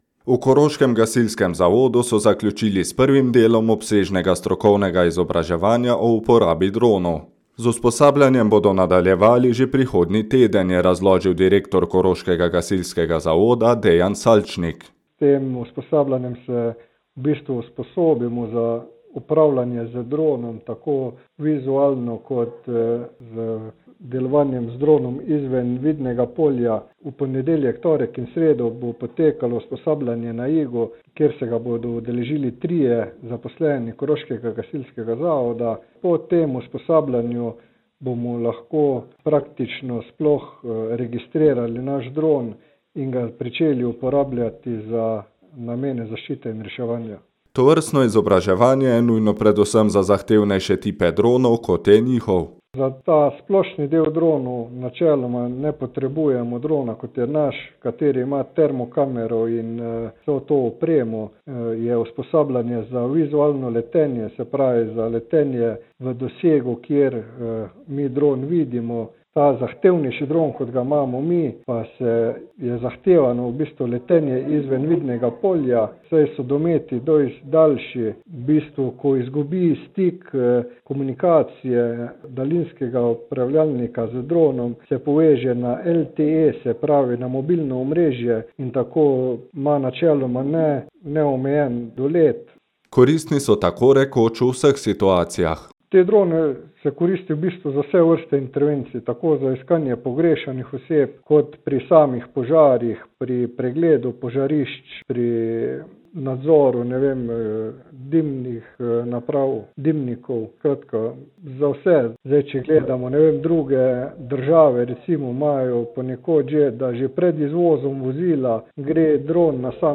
Izjava gasilci droni.mp3